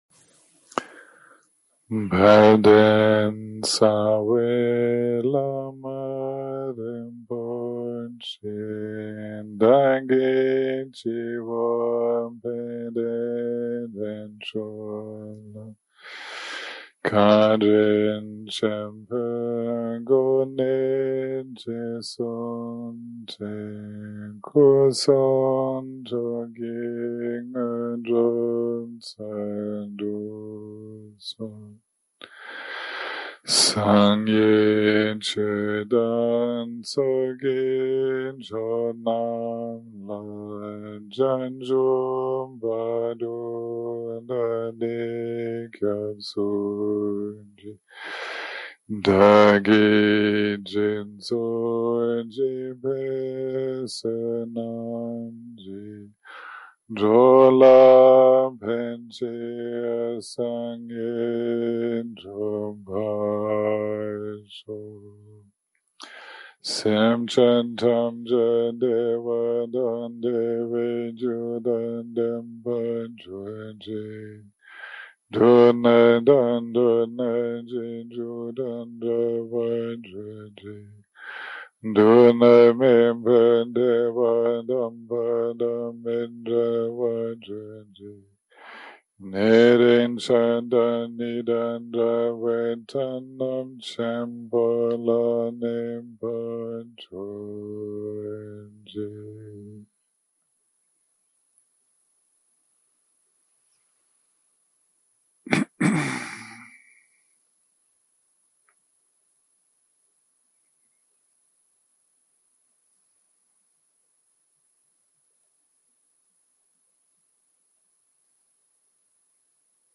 day 3 - recording 8 - Morning - Meditation Guidance
Dharma type: Guided meditation שפת ההקלטה